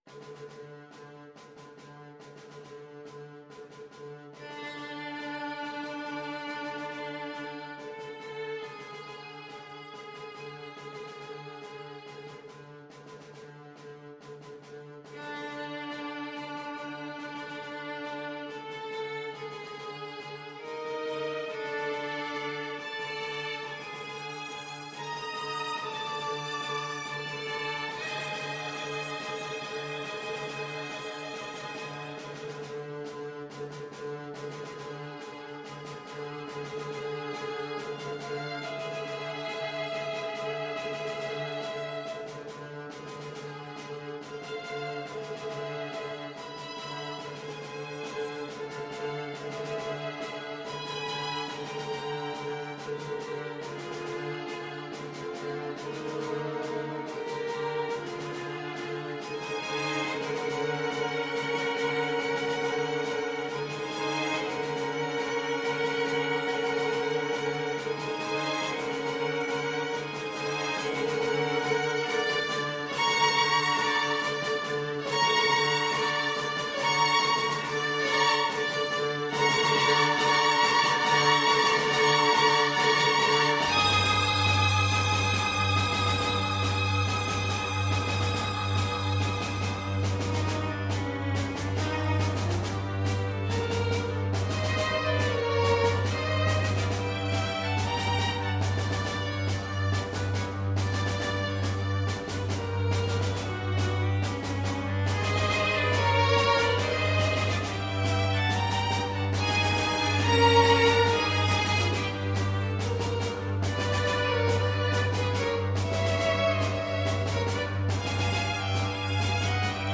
Violin
Viola
Double Bass
Side Drum
Allegro = 140 (View more music marked Allegro)
5/4 (View more 5/4 Music)